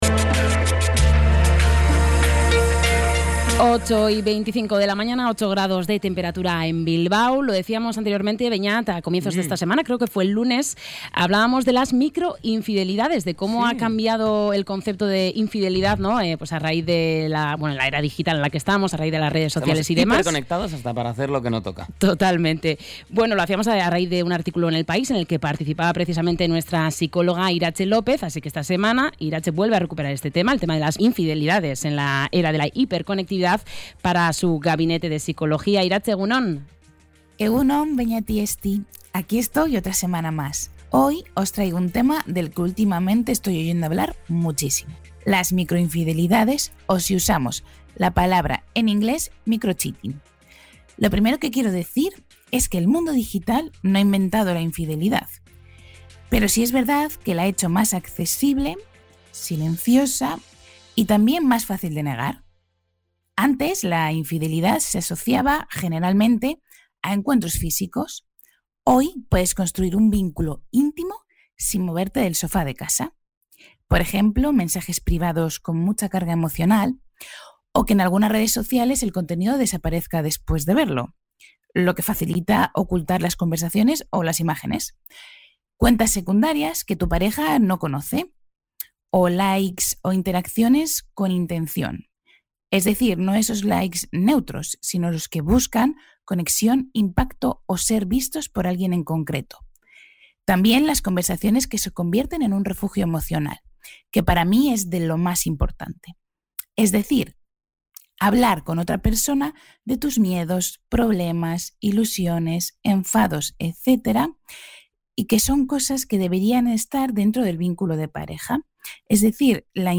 Podcast Bilbao